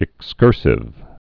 (ĭk-skûrsĭv)